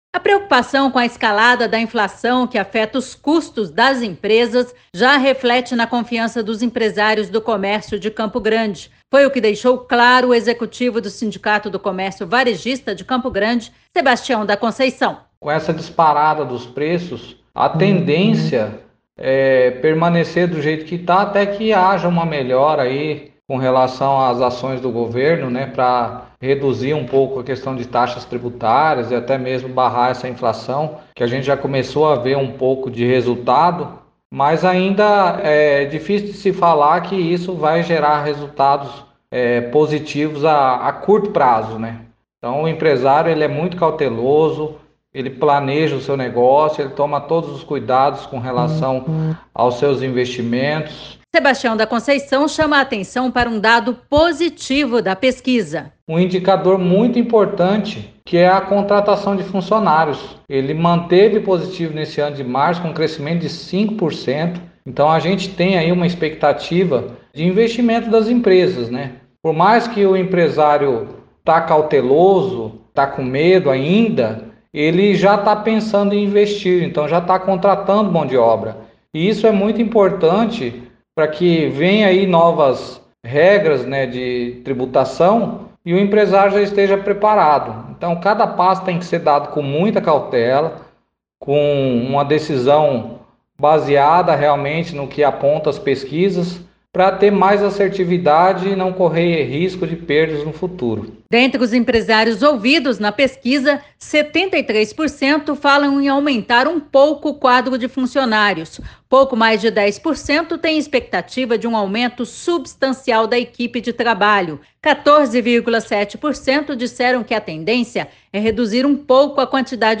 Saiba tudo na reportagem